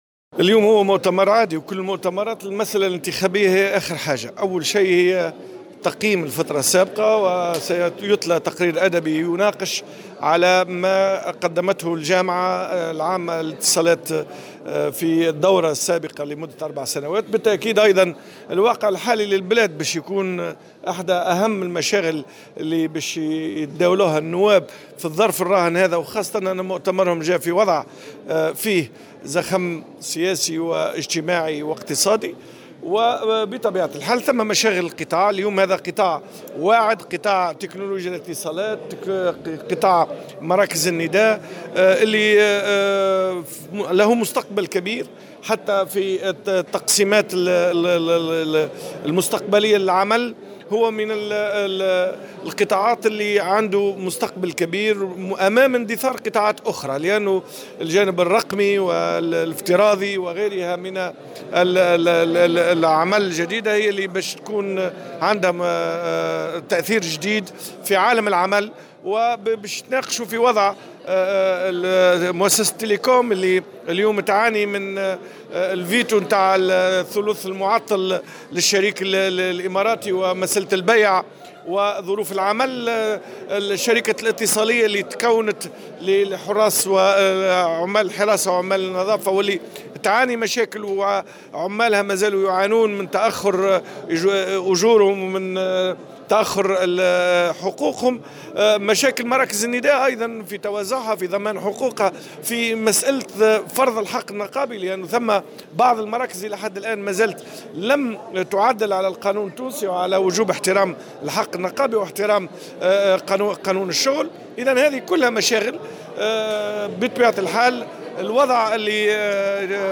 على هامش انعقاد مؤتمر الجامعة العامة للاتصالات بالحمامات